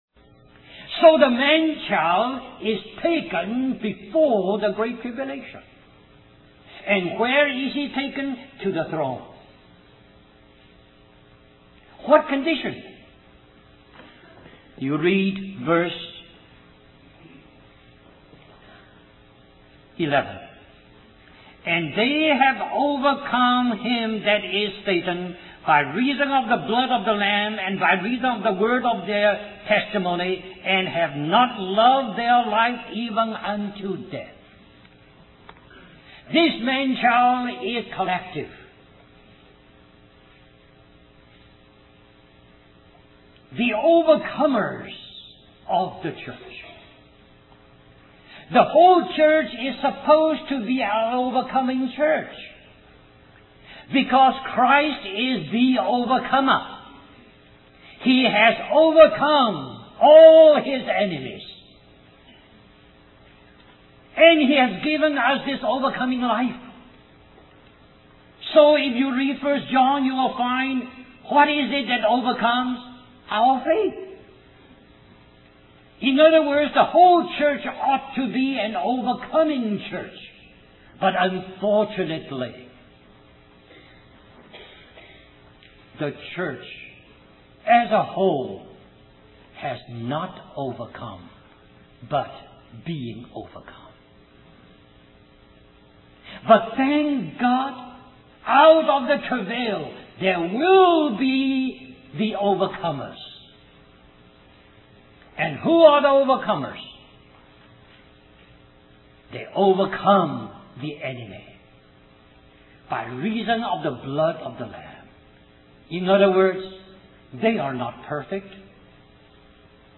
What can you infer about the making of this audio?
1989 Christian Family Conference Stream or download mp3 Summary This message is a continuation of this message.